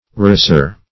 Rasure \Ra"sure\ (r[=a]"zh[-u]r; 135), n. [L. rasura, fr.